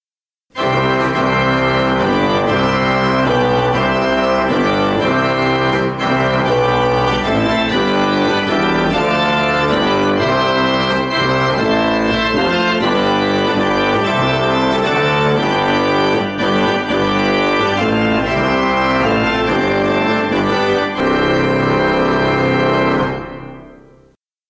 Instrumental hören Sie Nun lasst uns Gott dem Herren Dank sagen und ihn ehren für alle seine Gaben, die wir empfangen haben.